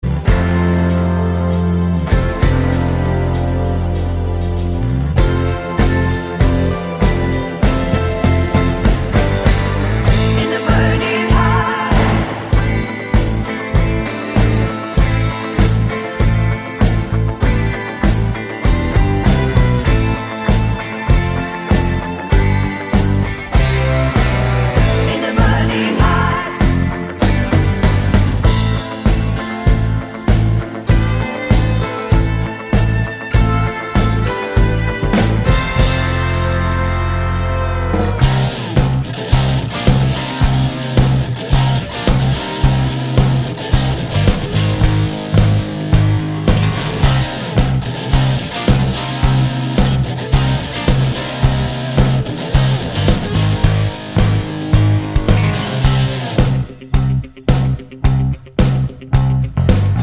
Twofers Medley Rock 3:12 Buy £1.50